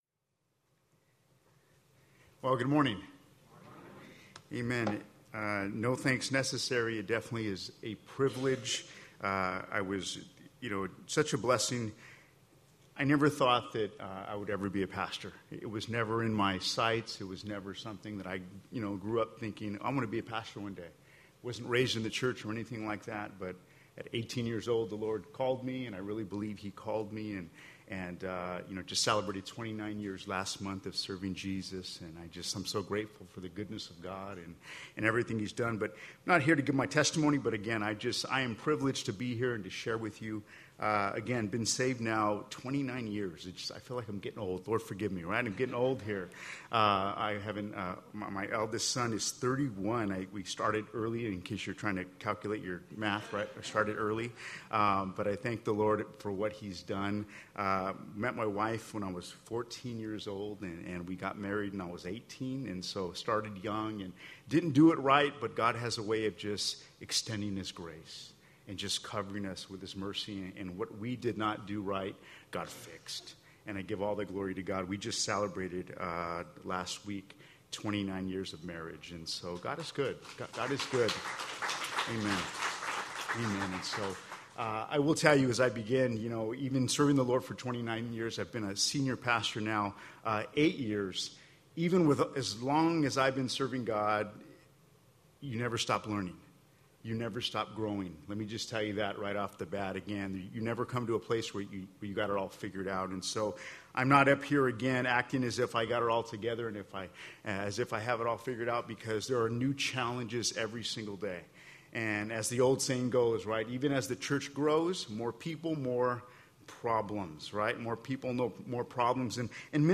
Galatians 6:9 Service: Servants Conference Bible Text